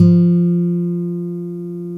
Index of /90_sSampleCDs/Roland L-CDX-01/GTR_Nylon String/GTR_Nylon Chorus